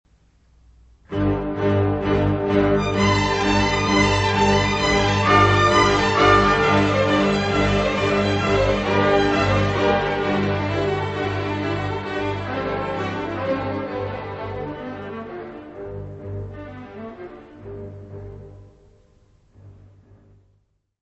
Peer Gynet, incidental music
: stereo; 12 cm + folheto
Área:  Música Clássica